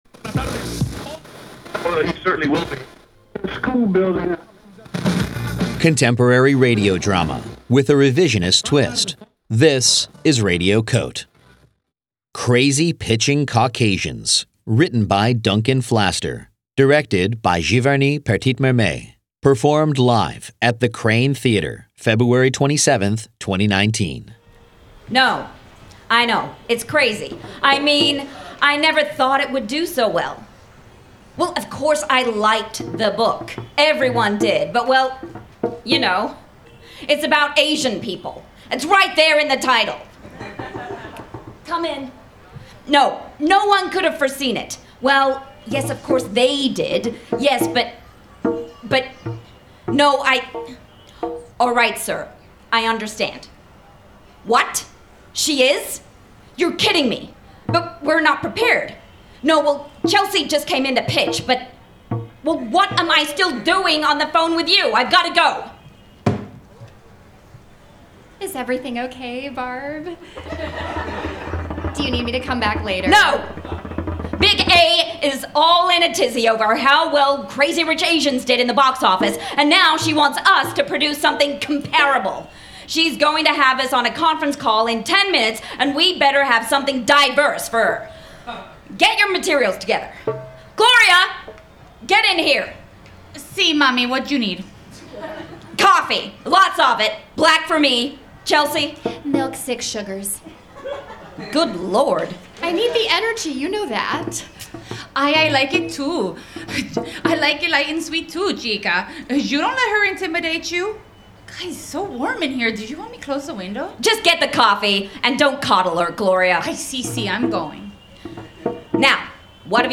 performed live at Radio COTE: 24-hour Newsroom in the 2019 FRIGID Festival at The Kraine Theater, New York City, February 27, 2019